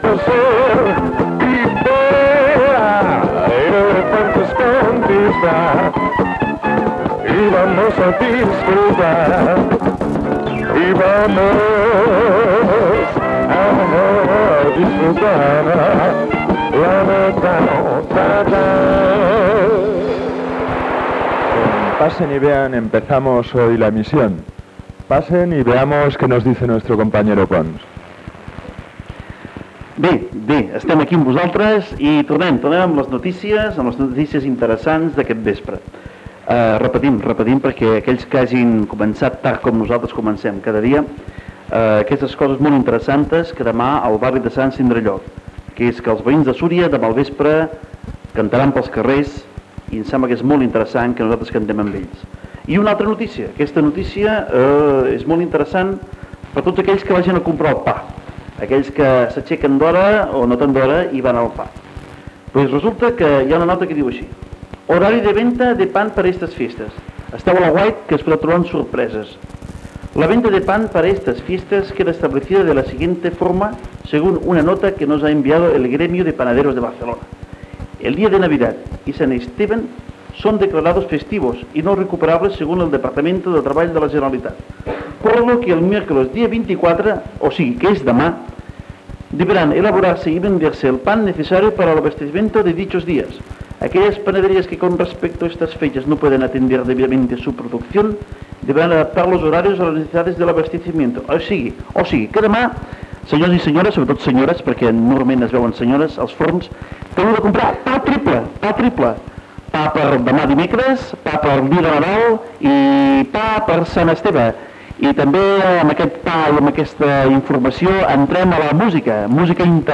c83480dcc24efa7e3b5ffa485baee61061a2fd16.mp3 Títol Ràdio Llibertina Emissora Ràdio Llibertina Titularitat Tercer sector Tercer sector Lliure Descripció Inici d'emissió. Horaris dels forns de pa per Nadal. Tema musical. Tall de llum a La Perona.